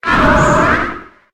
Cri de Tutétékri dans Pokémon HOME.